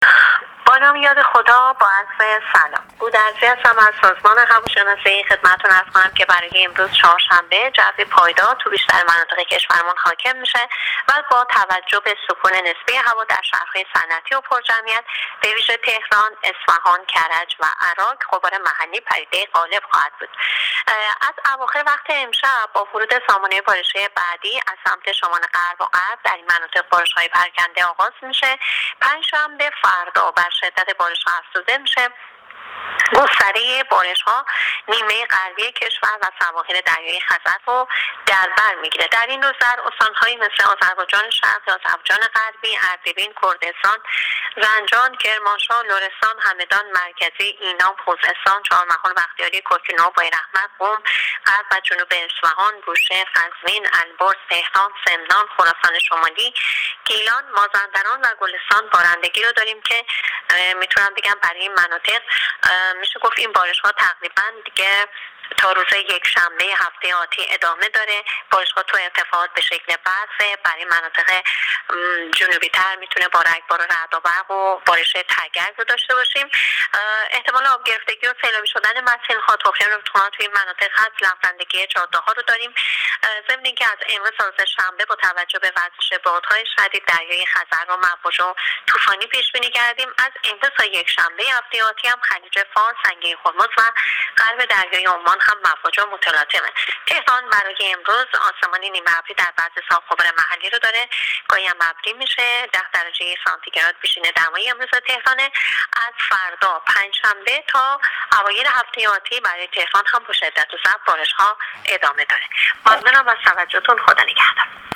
کارشناس سازمان هواشناسی کشور در گفت‌وگو با رادیو اینترنتی وزارت راه و شهرسازی، آخرین وضعیت آب و هوای کشور را تشریح کرد.
گزارش رادیو اینترنتی از آخرین وضعیت آب‌‌و‌‌‌هوای پنجم آذر